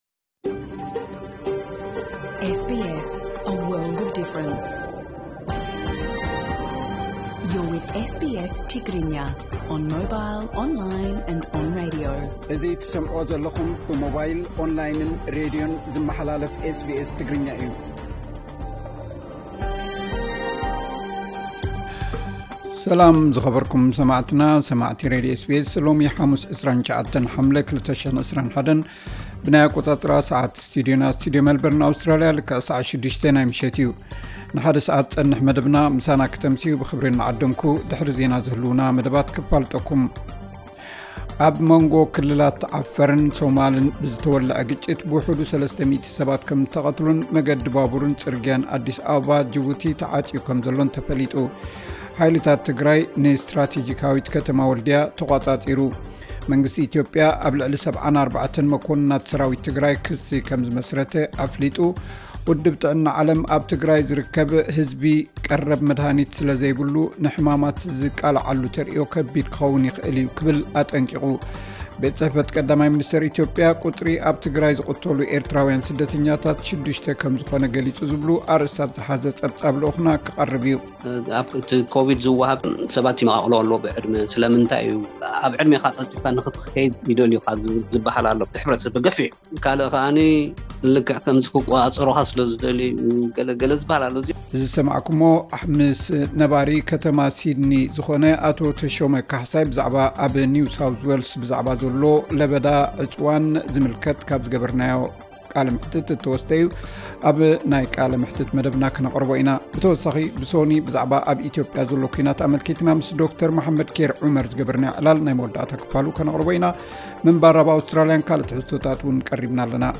ዕለታዊ ዜና 29 ሓምለ 2021 SBS ትግርኛ